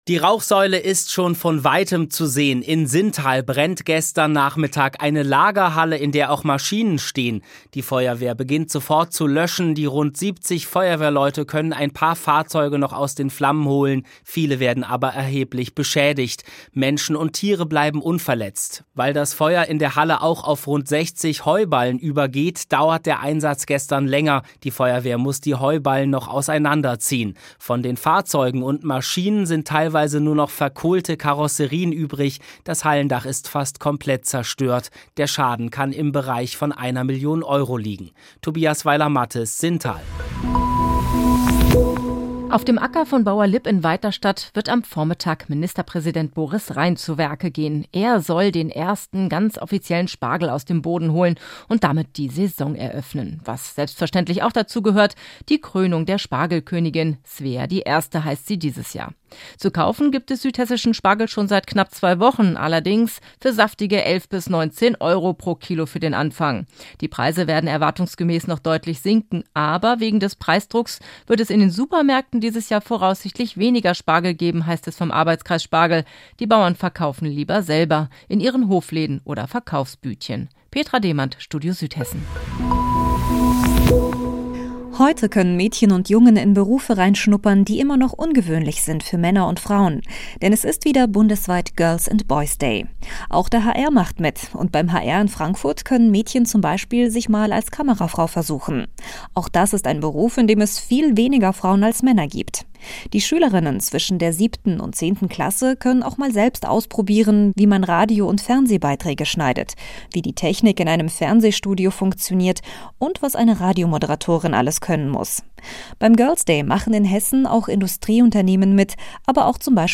Mittags eine aktuelle Reportage des Studios Frankfurt für die Region